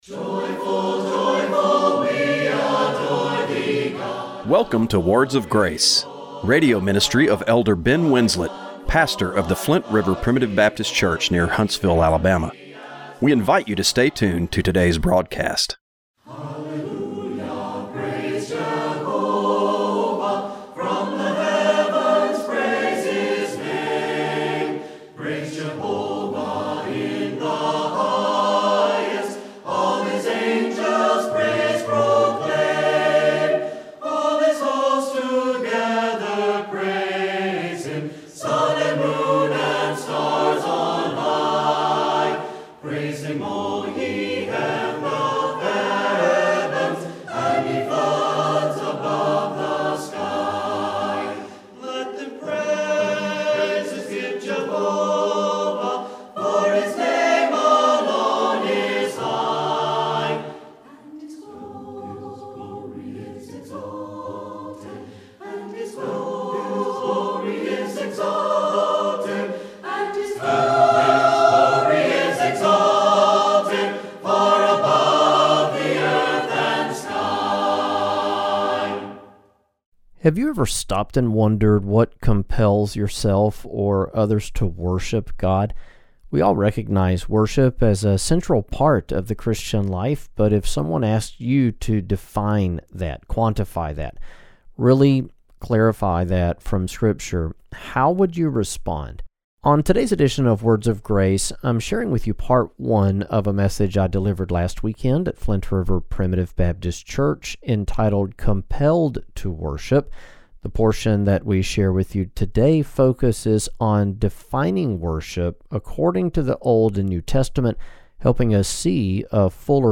Radio program for August 17, 2025.